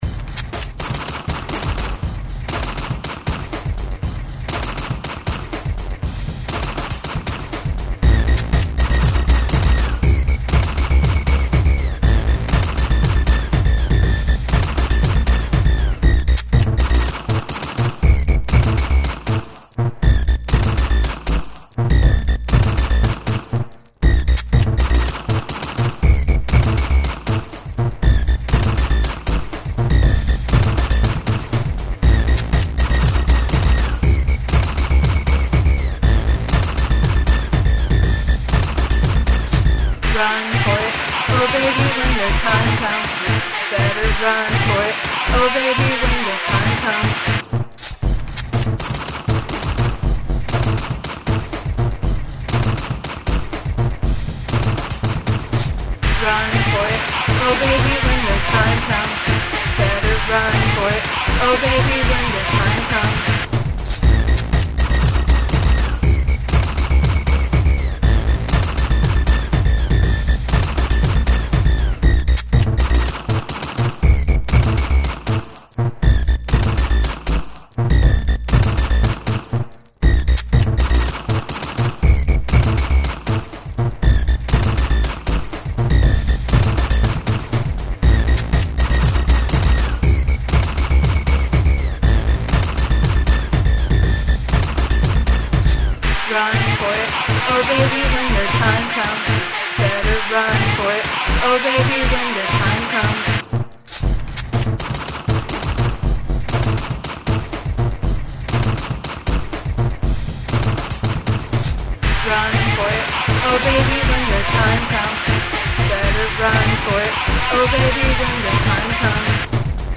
Use of the sound of gunfire
Okay this is pretty musical with the gunfire too.
Good chip chop job here and use of gun samples.
Cold, which is fine for gun themes.